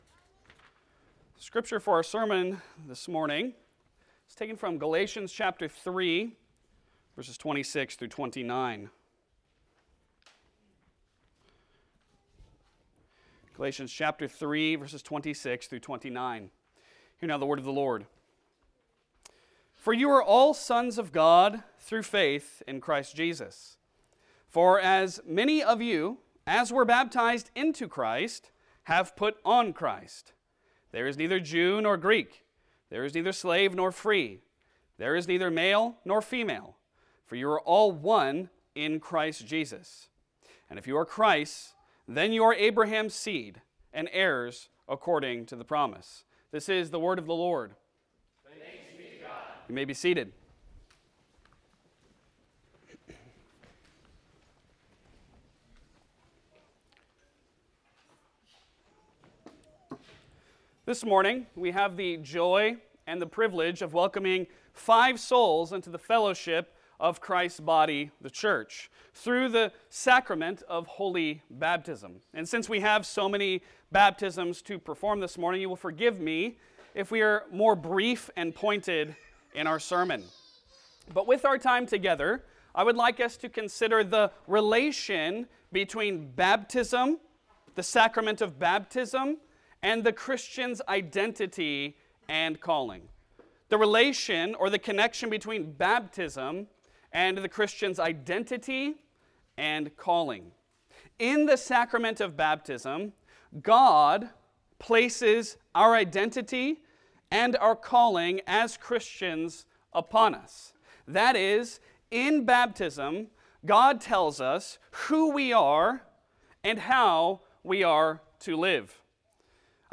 Passage: Galatians 3:26-29 Service Type: Sunday Sermon